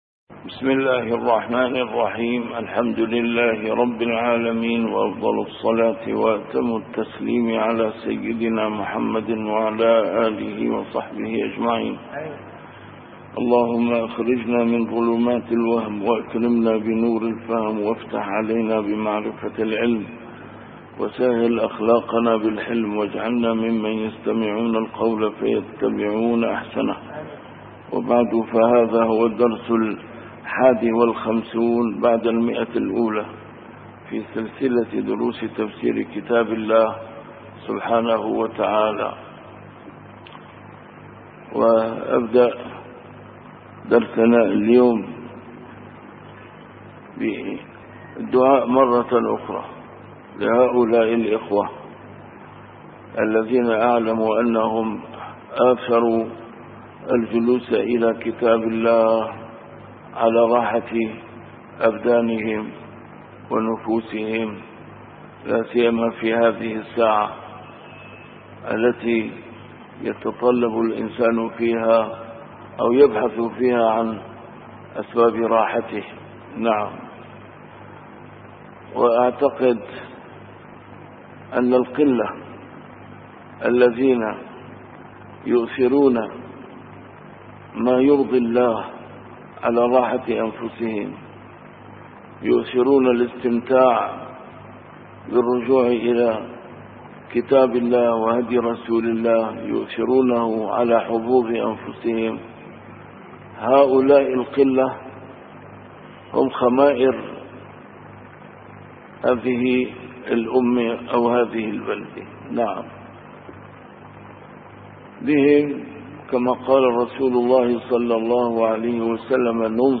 A MARTYR SCHOLAR: IMAM MUHAMMAD SAEED RAMADAN AL-BOUTI - الدروس العلمية - تفسير القرآن الكريم - تفسير القرآن الكريم / الدرس الواحد والخمسون بعد المائة: سورة آل عمران: الآية 7